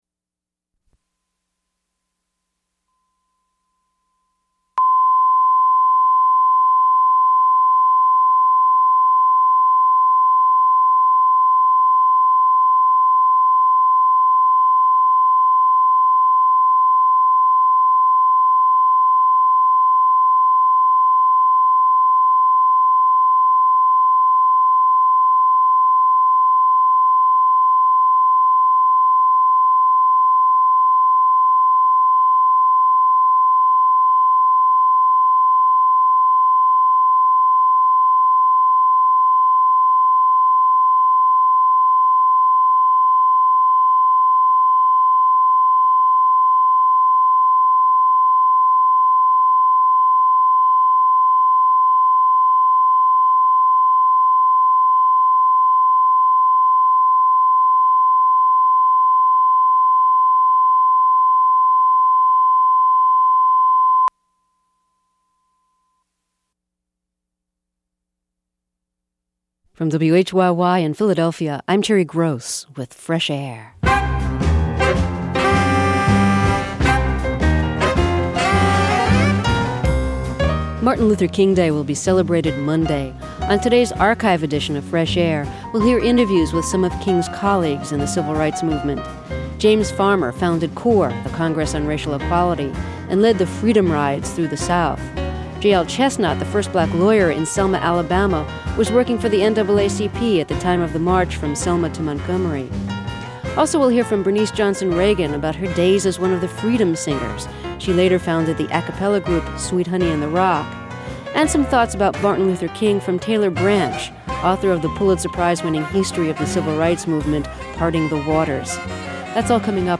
This interview was originally broadcast on 12/05/1988.